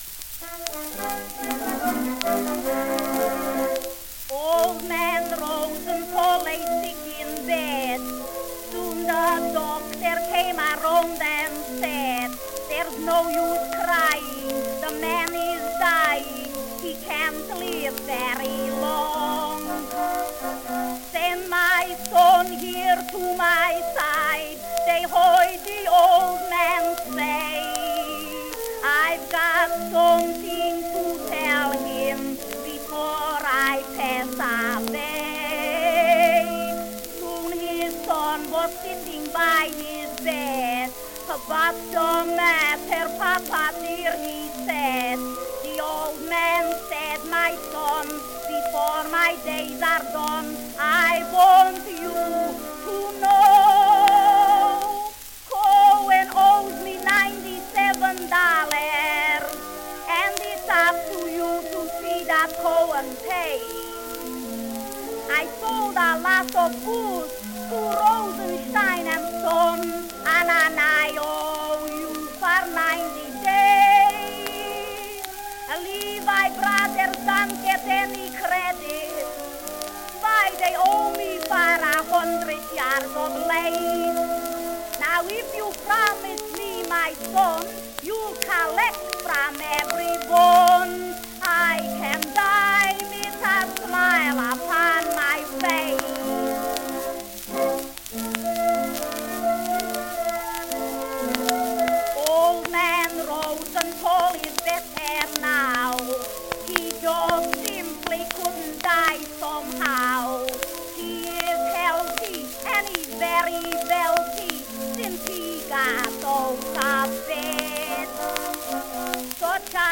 The record describes the song as a "Hebrew dialect song."
Popular music--1911-1920
shellac